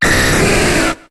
Cri de Dracolosse dans Pokémon HOME.